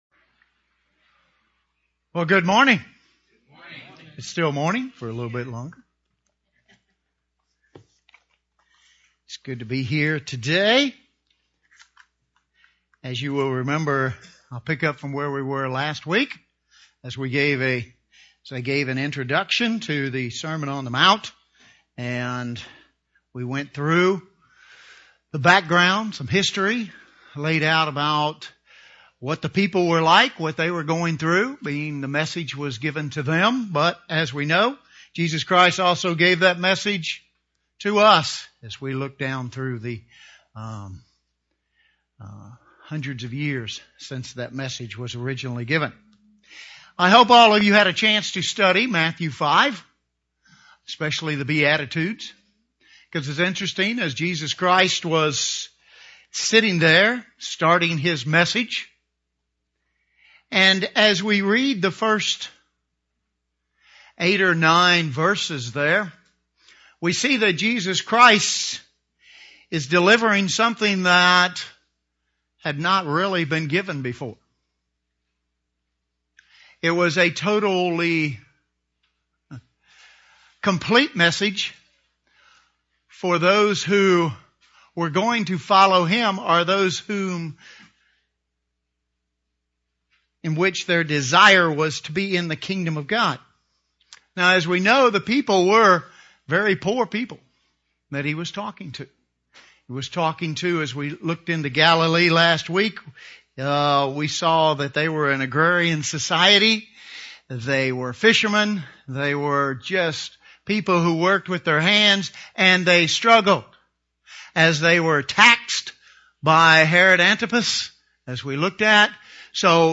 The beatitudes can be viewed as a progression of Christian growth. This semon examines the first seven beatitudes.